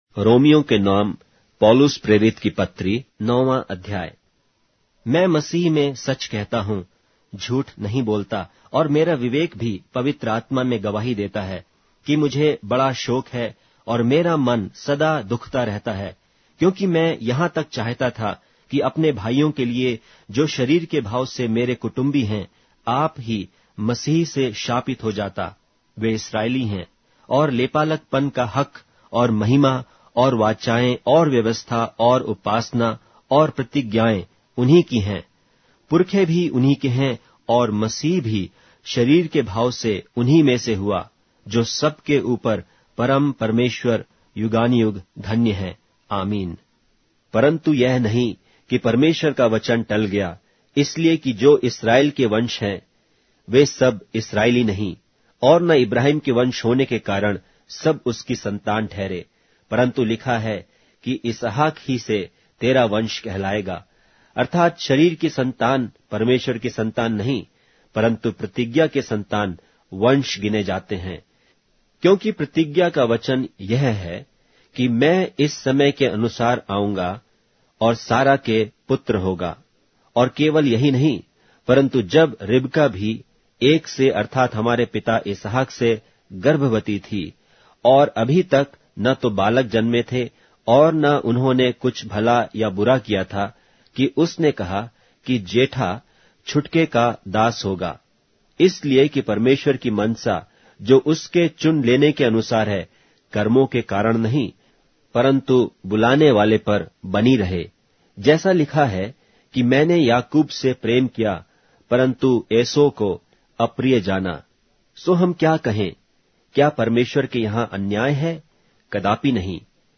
Hindi Audio Bible - Romans 9 in Mov bible version